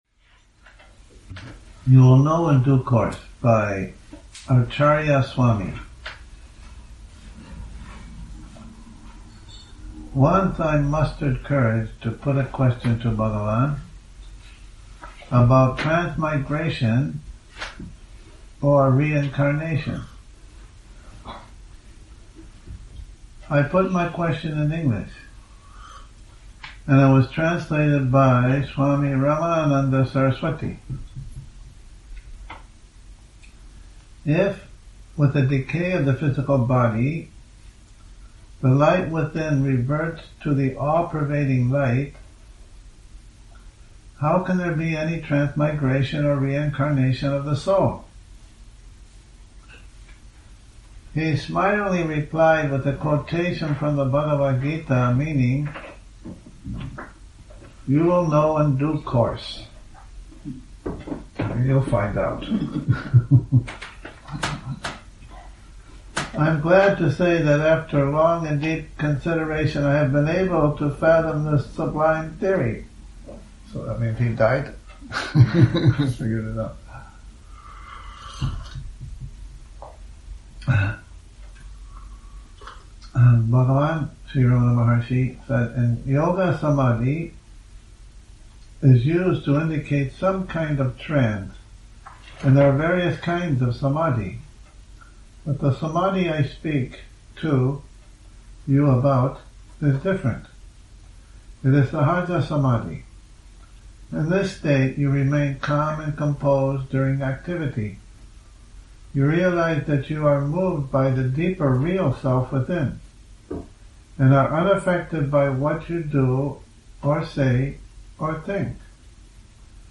Morning Reading, 09 Nov 2019